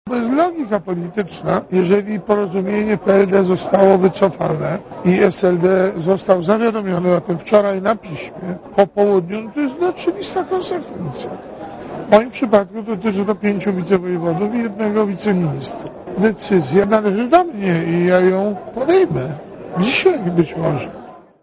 Dla Radia Zet mówi Józef Oleksy (83 KB)